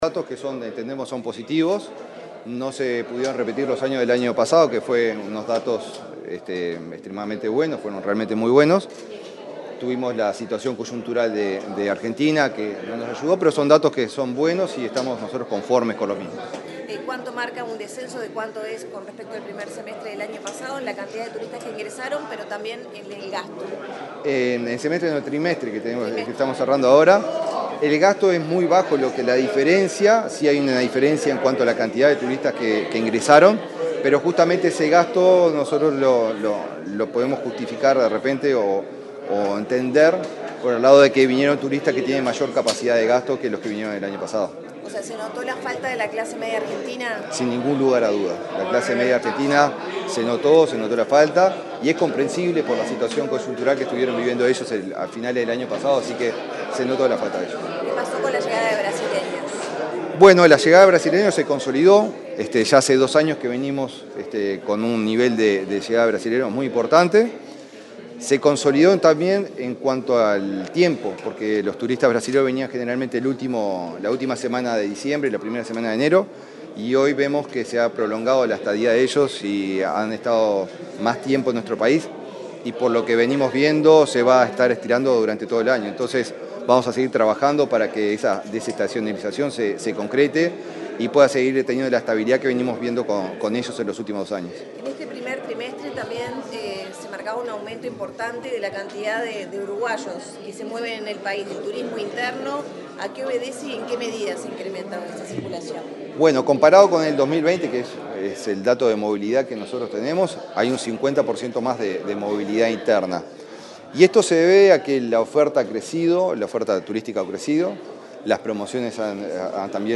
Declaraciones del ministro de Turismo, Eduardo Sanguinetti
El ministro de Turismo, Eduardo Sanguinetti, informó a la prensa, acerca de los datos estadísticos del primer trimestre de 2024.